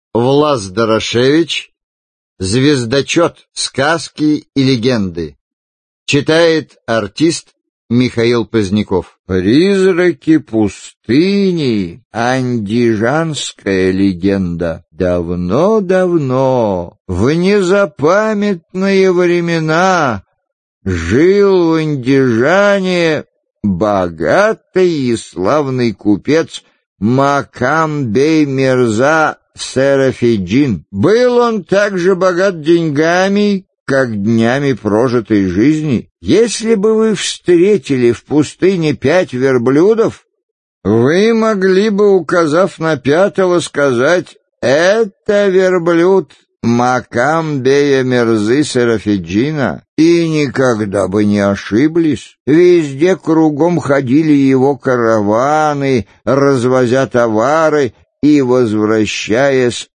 Аудиокнига Звездочёт. Сказки и легенды | Библиотека аудиокниг